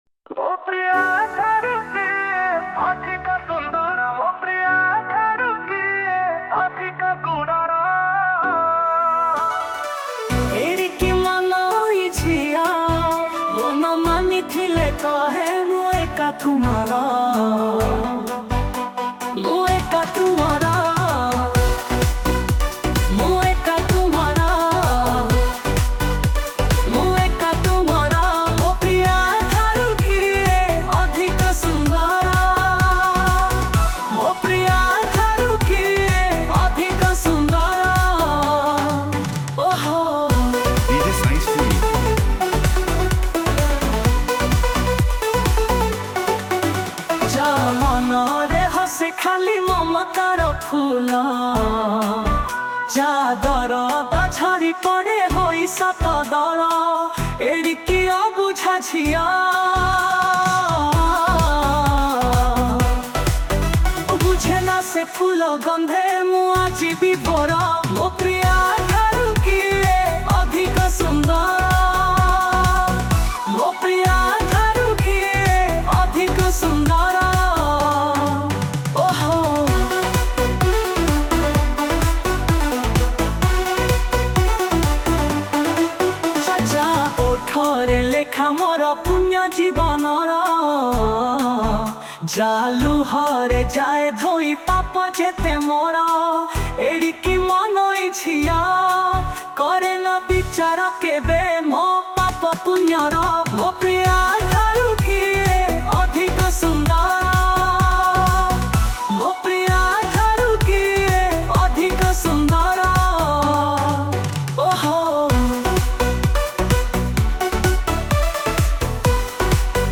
Romantic Love Dj Remix Songs Download